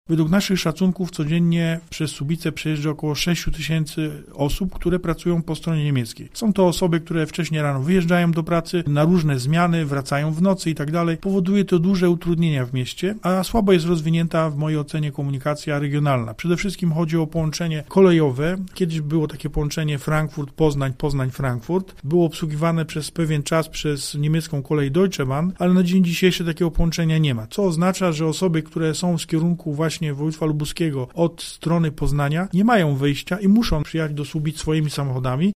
– Przyjeżdżają do naszego miasta samochodami, zostawiają je, przesiadają się do niemieckich środków komunikacji i jadą dalej – tłumaczy Tomasz Ciszewicz, burmistrz Słubic: